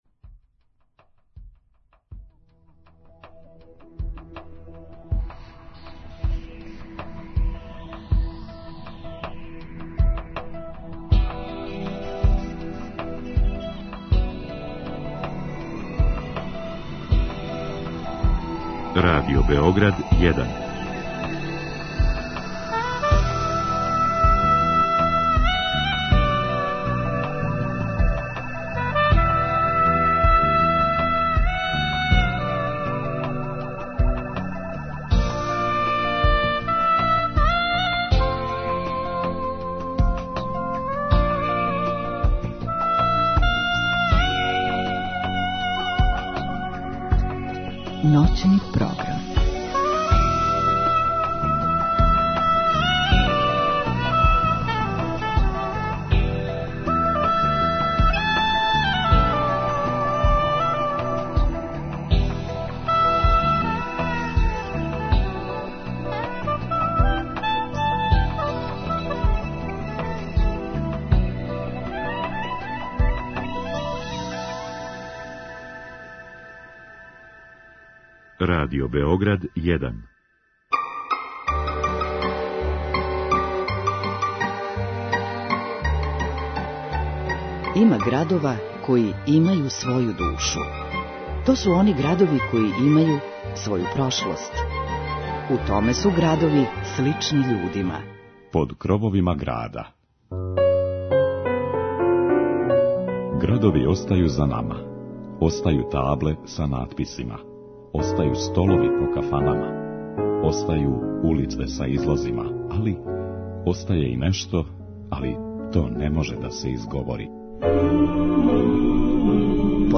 У вечерашњој емисији слушаћете музику југа Србије, уз интересантне приче о настанку појединих песама.